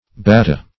Batta \Bat"ta\ (b[a^]t"t[.a]), n. [Prob. through Pg. for